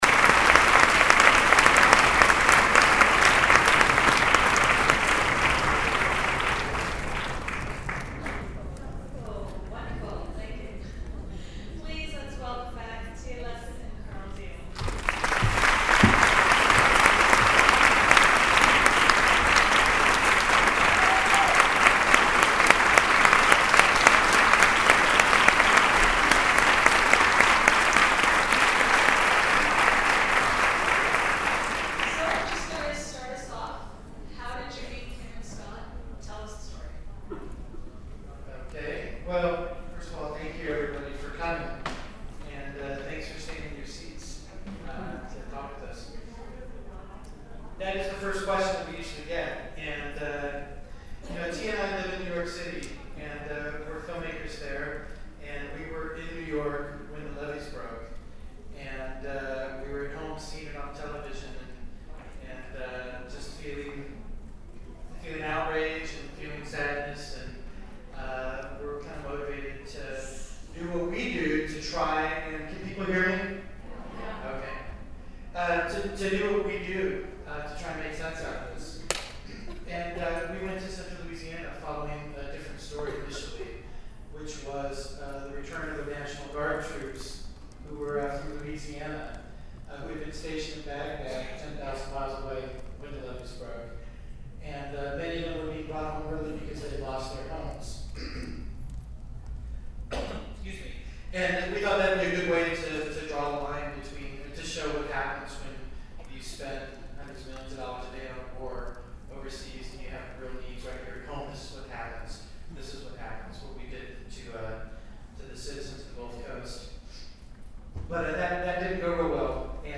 troublethewater_qa.mp3